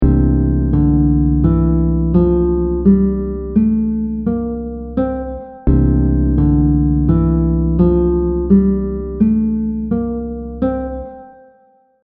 The audio examples will repeat the scale over a chord so you can get a better grasp of the sound of each mode.
• Mood / emotion: laid back, smooth 😎
• Characteristic note: flat 7
You can think of the Mixolydian as a Major scale with a flat 7th degree.
C Mixolydian scale audio example